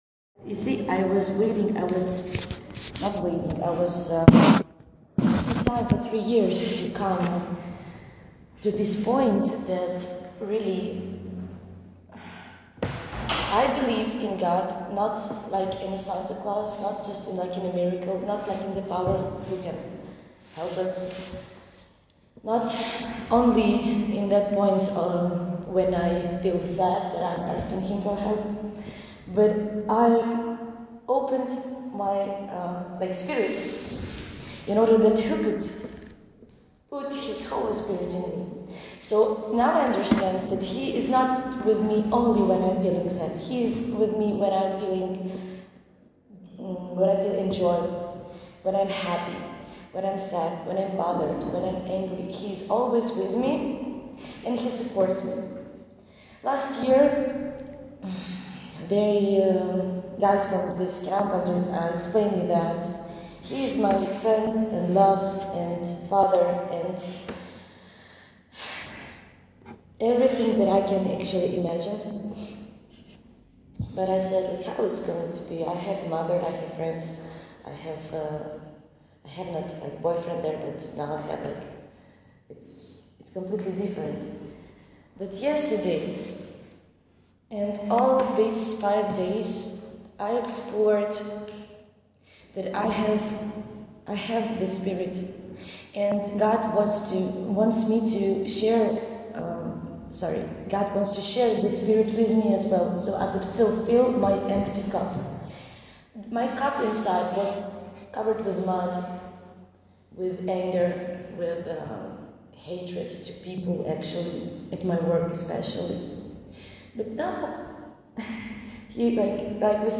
July 19, 2015      Category: Testimonies      |
A new Ukrainian believer gives their testimony.